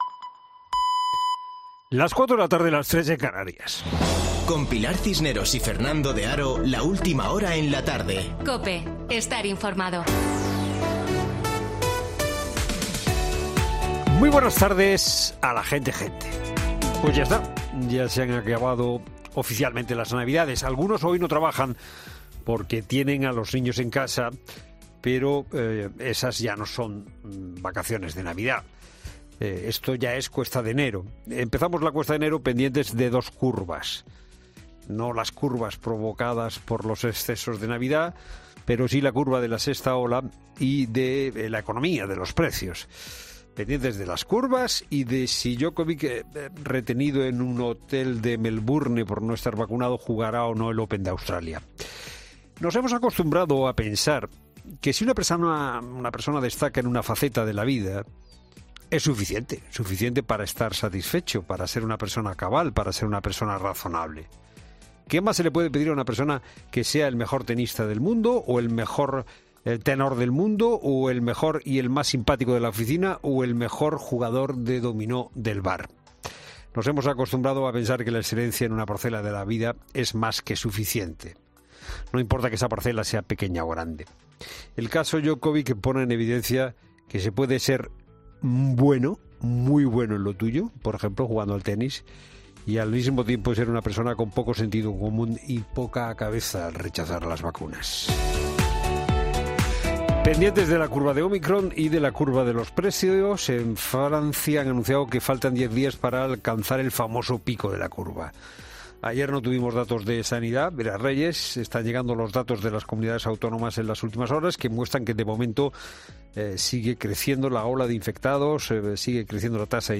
Boletín de noticias COPE del 7 de enero de 2022 a las 16:00 horas